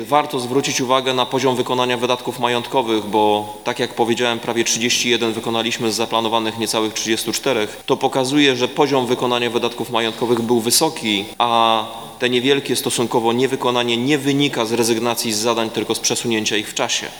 Niemal w całości udało się wykonać zakładane wydatki majątkowe – mówił podczas obrad Rafał Zając.